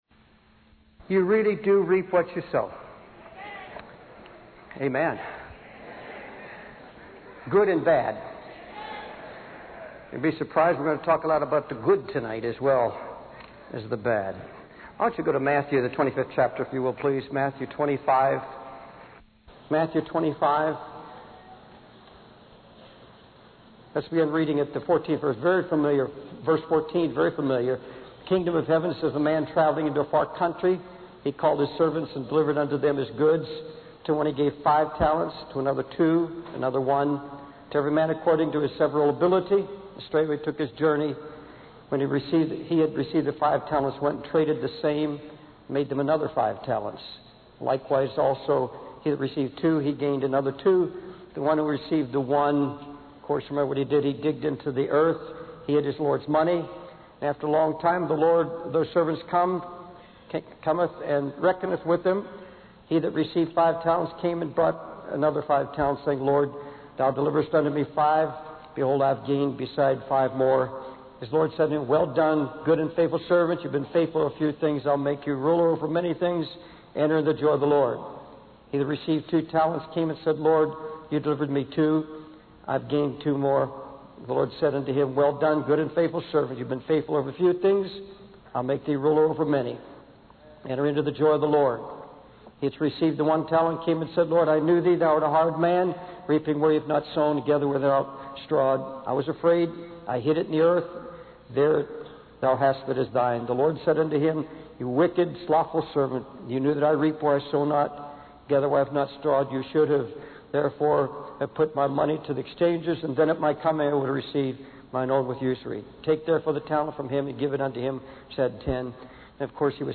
In this sermon, the preacher discusses the parable of the talents from Matthew 25.